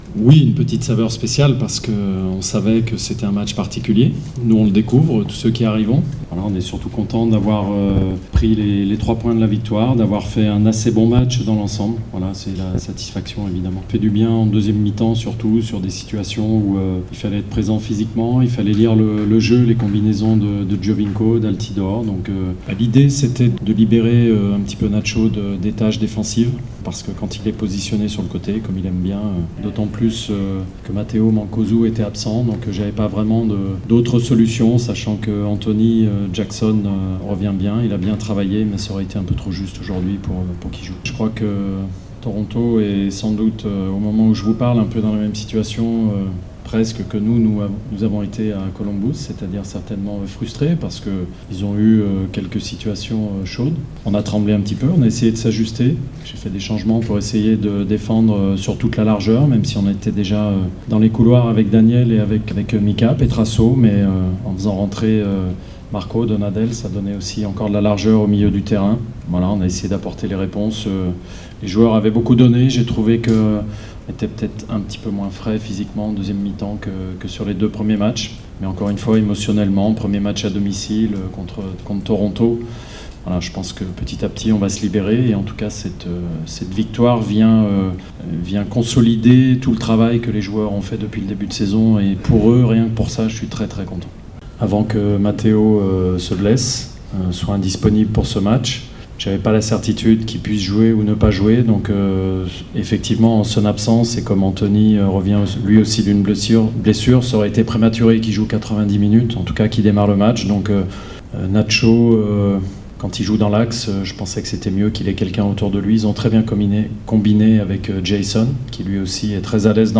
Le interviste del post-partita: